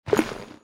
drop_1.wav